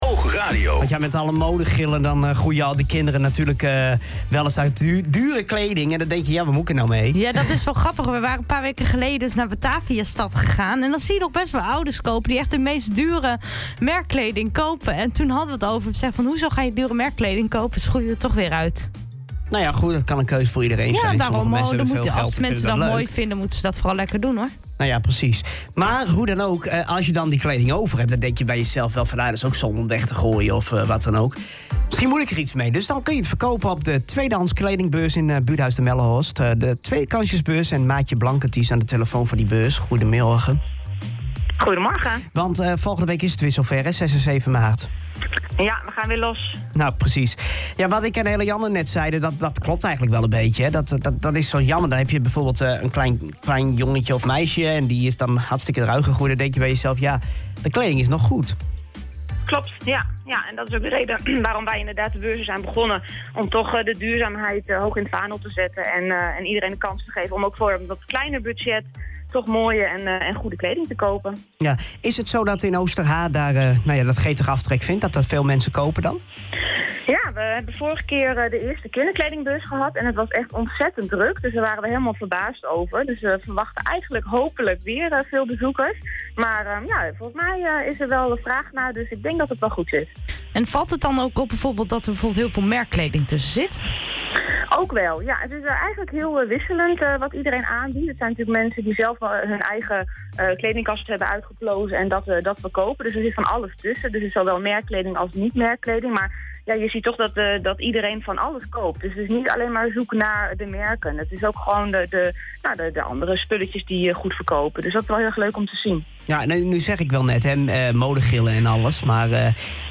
Luister hier het interview uit Haren Doet terug: Kledingbeurzen 2e Kansjes in Oosterhaar De dameskledingbeurs vindt plaats op vrijdagavond 6 maart van 19:30 tot 22:00 uur en de kinderkledingbeurs op zaterdag 7 maart van 10:00 tot 12:30 uur.